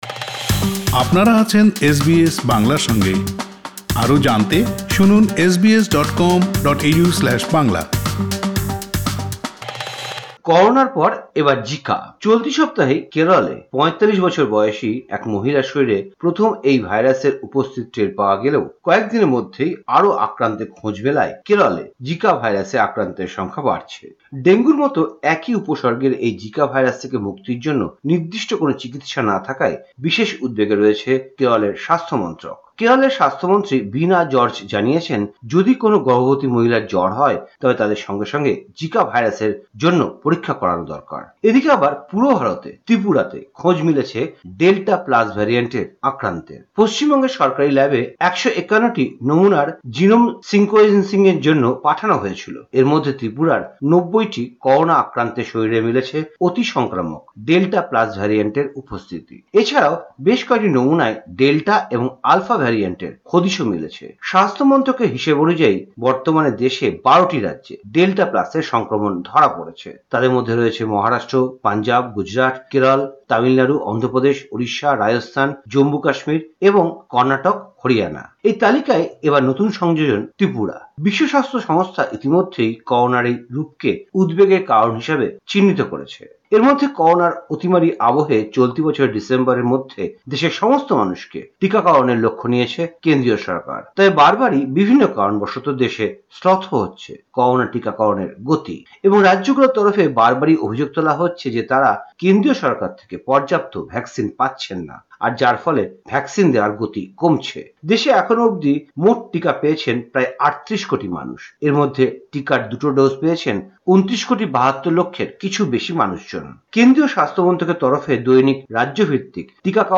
ভারতীয় সংবাদ: ১২ জুলাই ২০২১